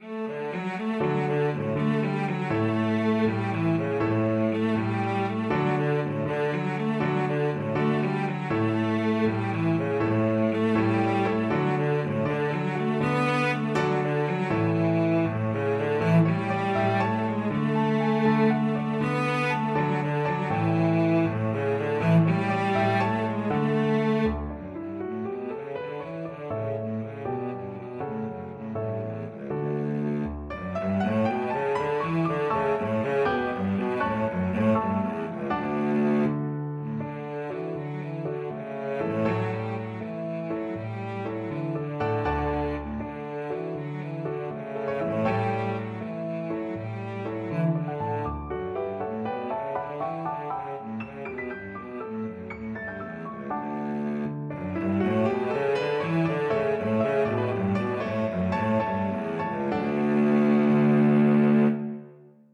6/8 (View more 6/8 Music)
Moderato . = c.80
Db3-B4
Traditional (View more Traditional Cello Music)
world (View more world Cello Music)